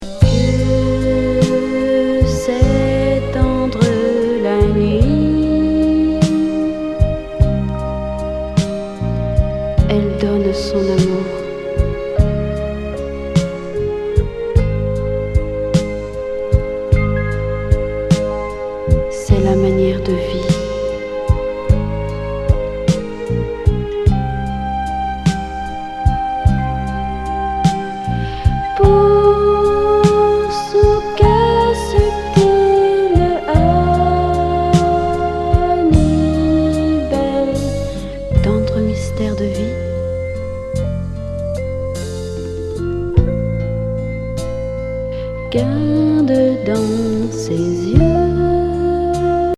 舌足らずウィスパー・ボーカル・バラード♪